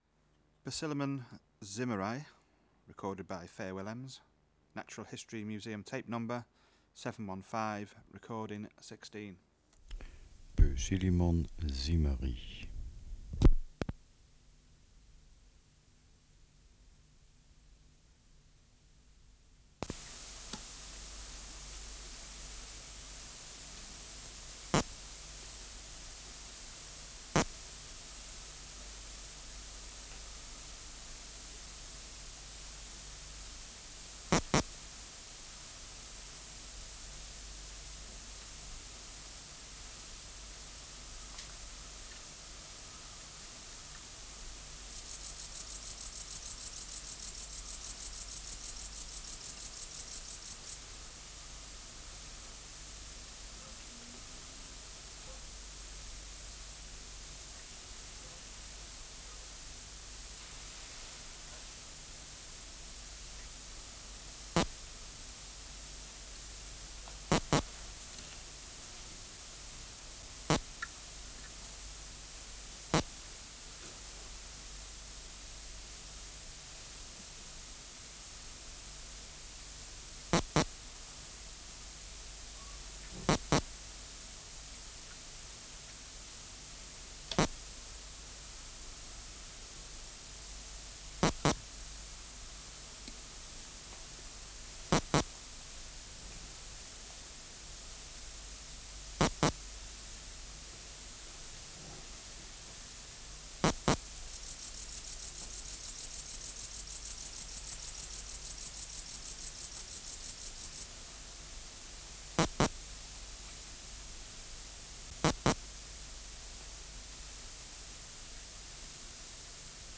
Species: Poecilimon (Poecilimon) zimmeri
Air Movement: Nil
Extraneous Noise: Pholidoptera macedonica (Uher 66-68 and 82-84) Substrate/Cage: In cage Biotic Factors / Experimental Conditions: Isolated male
Microphone & Power Supply: AKG D202 (LF circuit off) Distance from Subject (cm): 4